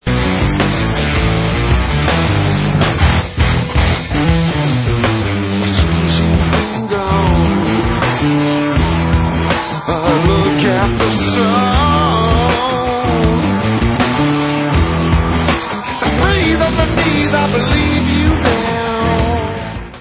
Allstar dutch rockband w/ex-anouk & kane members